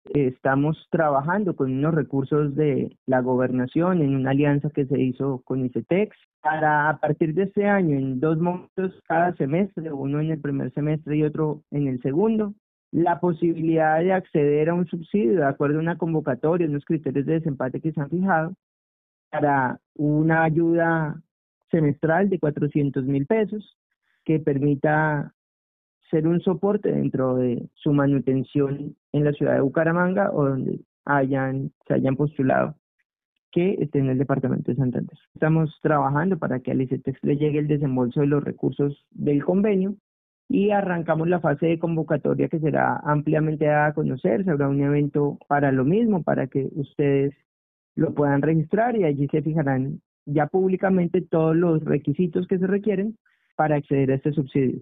Nicolás Ordóñez, Secretario de Educación de Santander